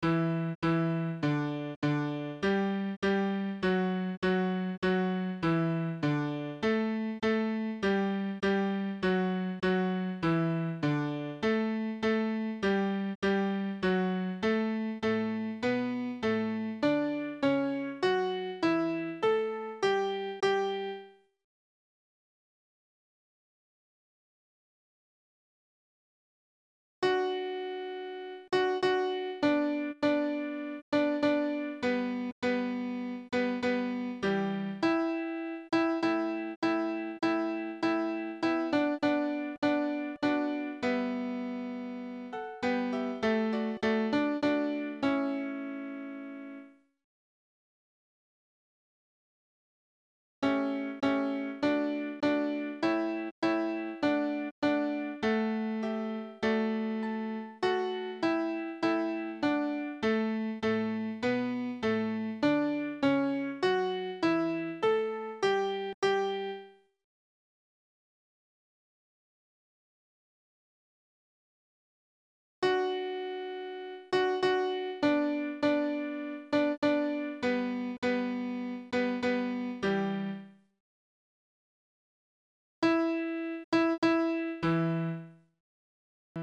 Tutti Soprane Alto Ténor Basse
Andante ma con tanto